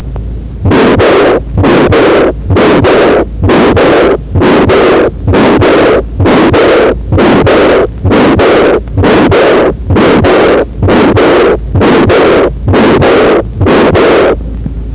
to and fro murmur   ซึ่งมีลักษณะเป็น mid-systolic ร่วมกับ early diastolic murmur พบในภาวะที่มี VSD with AI หรือ AS with AI